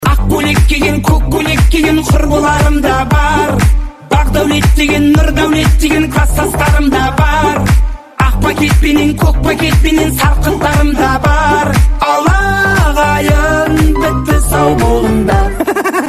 • Качество: 128, Stereo
мужской голос
забавные
энергичные